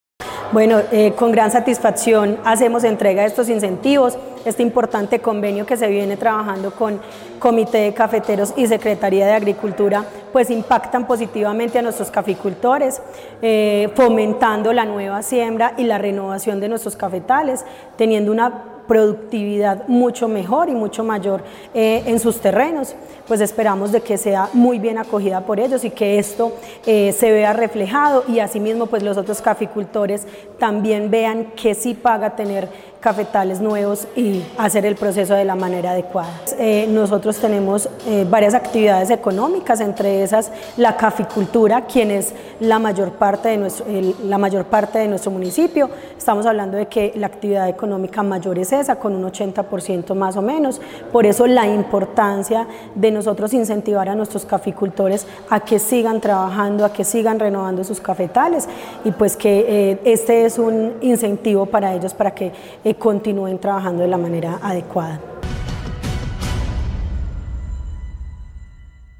Yeni Fernanda Henao Dávila, alcaldesa de La Merced.
Yeni-Fernanda-Henao-Davila-ENTREGAS-INCENTIVOS-CAFE-online-audio-converter.com_.mp3